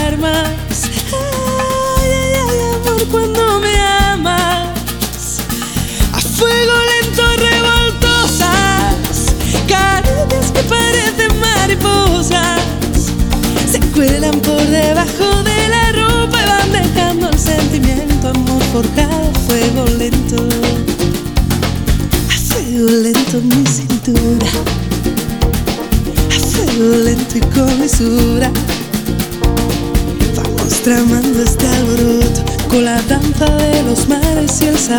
Pop Latino